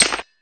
pickaxe_damage_stronk.ogg